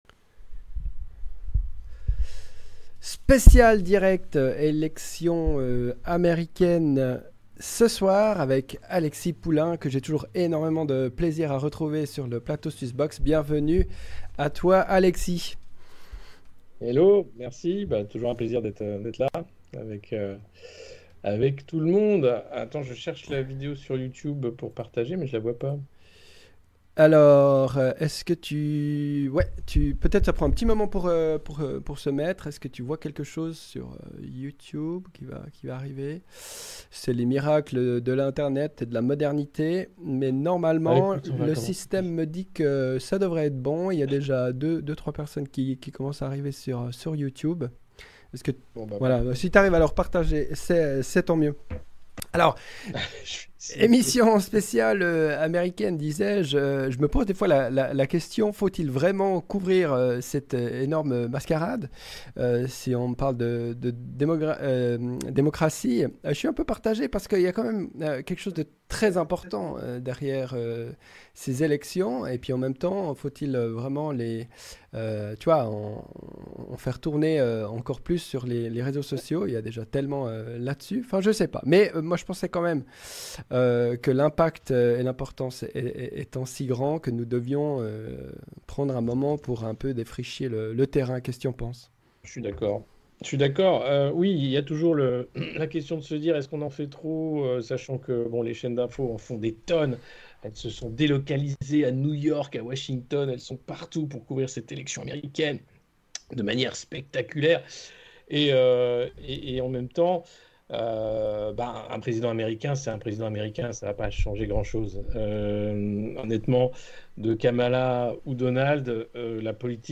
Dans ce live exceptionnel, nous analysons en direct les résultats et les enjeux des élections américaines